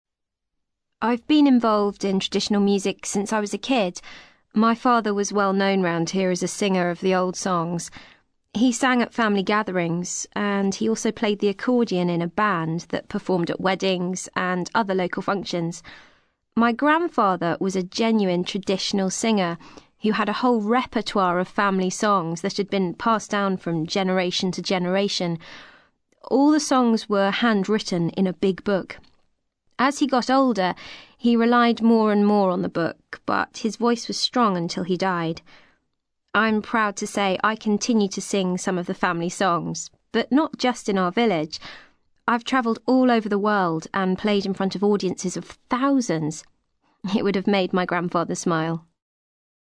ACTIVITY 183: You will hear five short extracts in which five people are talking about an aspect of music which is or has been important to them.